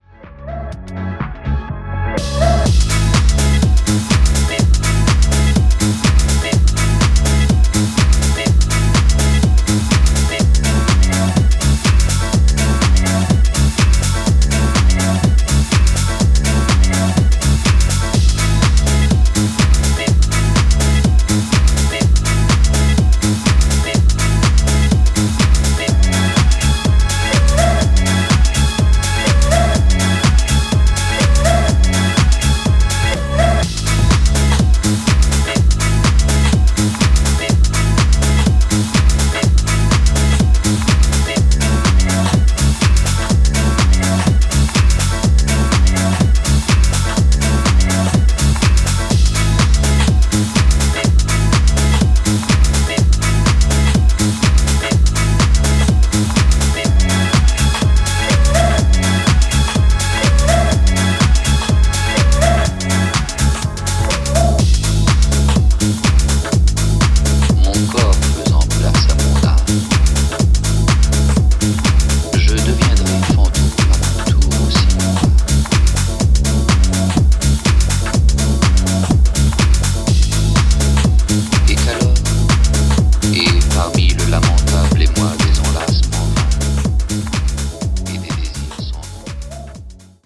全4曲リズミカルで爽やかなディスコ＆ディープなハウスサウンドに仕上がった大スイセンの1枚です！！
ジャンル(スタイル) DISCO HOUSE